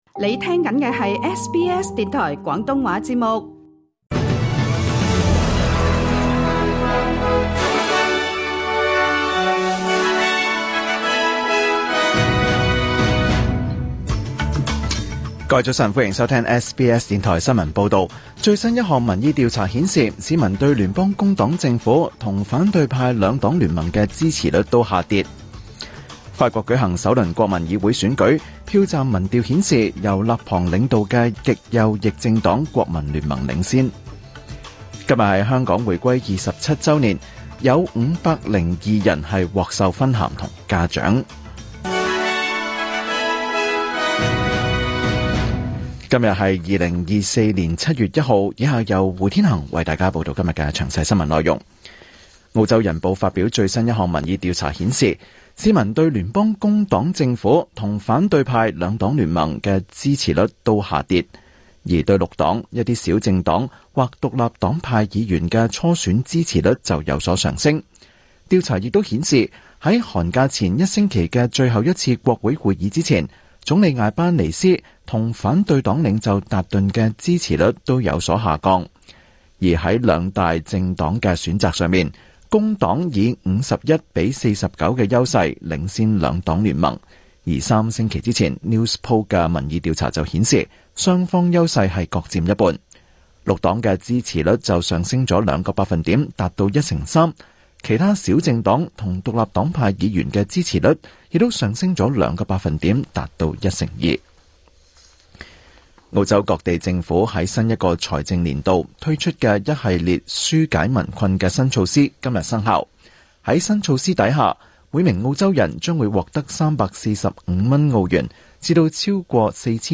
2024年7月1日SBS廣東話節目詳盡早晨新聞報道。